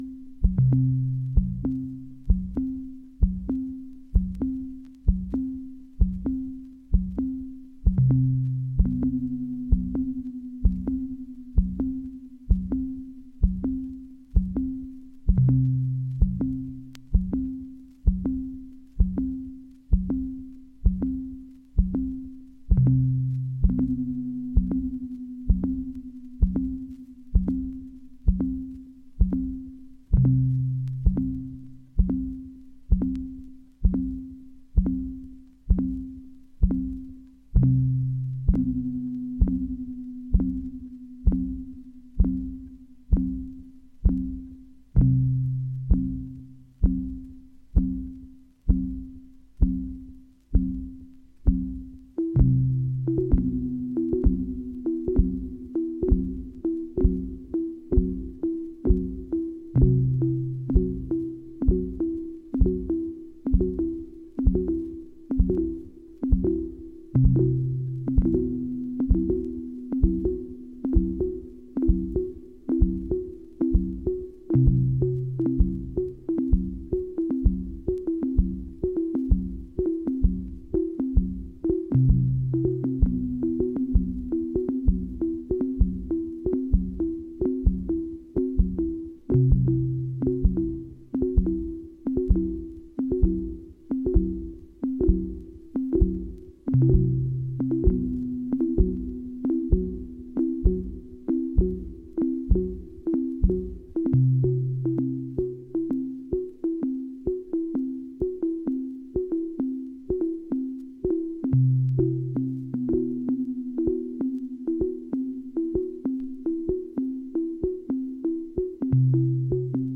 experimental and minimalist composition
exploring rhythm and tonality with a new vigour
electronic music
Serge and ARP Modular Synthesizer